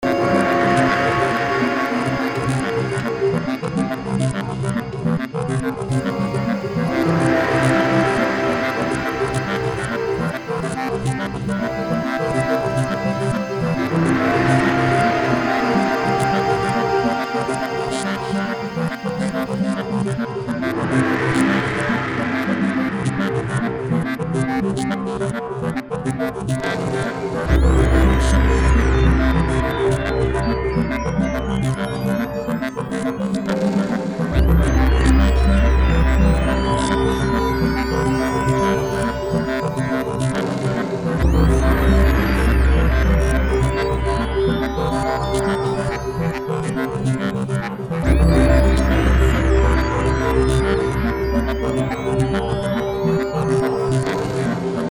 also this thing idk thinking about hypnosis. a spine scrubber...
this kind of distortion is so gooooood